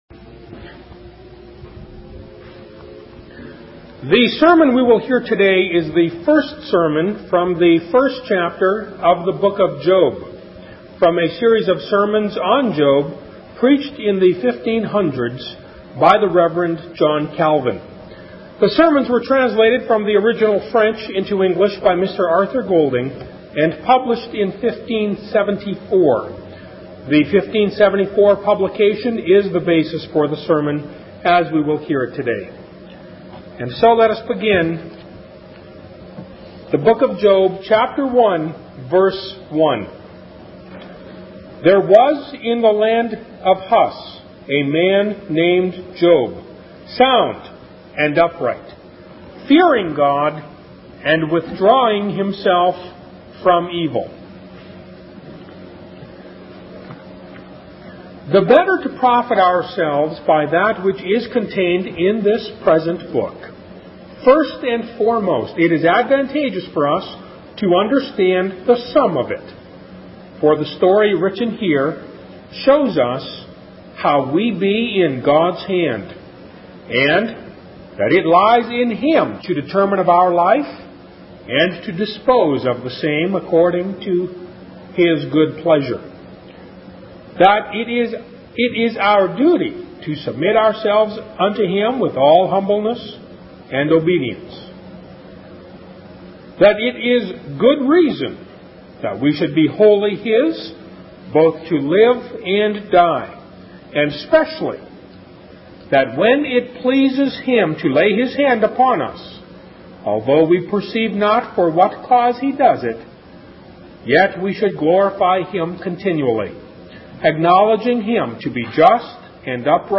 Sermons on Job #1 (Introduction) by John Calvin | SermonIndex